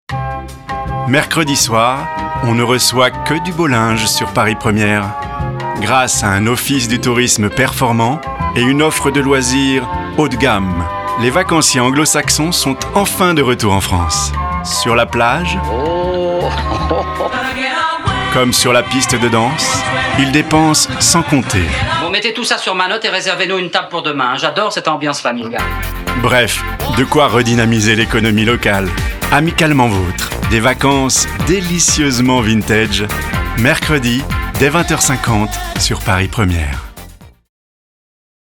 Voix off
- Baryton-basse